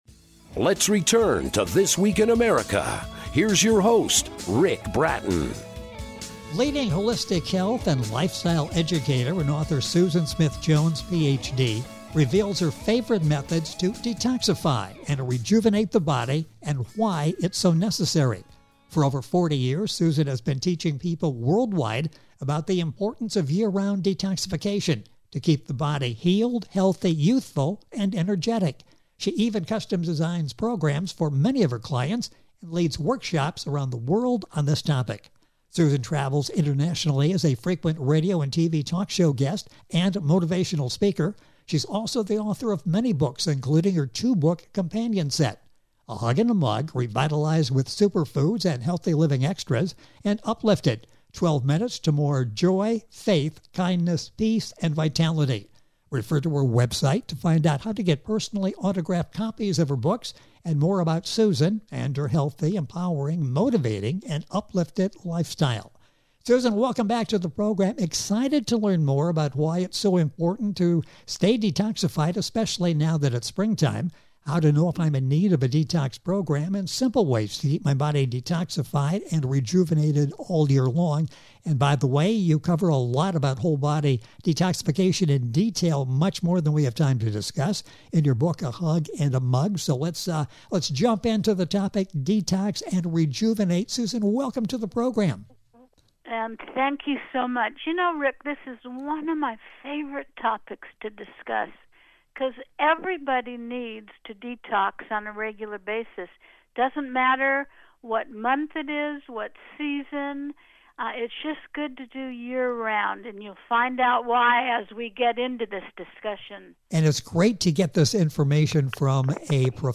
Detox Interview